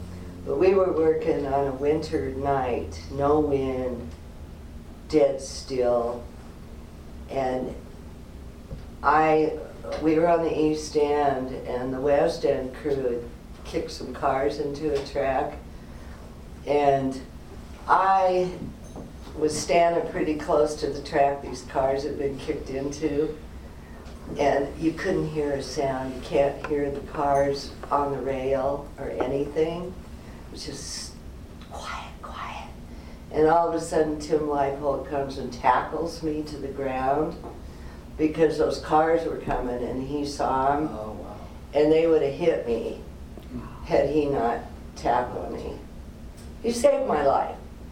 “Life Between the Rails” oral history project, Coll.